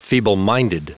Prononciation du mot feebleminded en anglais (fichier audio)
Prononciation du mot : feebleminded
feebleminded.wav